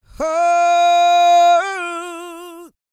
E-CROON 204.wav